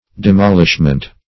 Demolishment \De*mol"ish*ment\, n.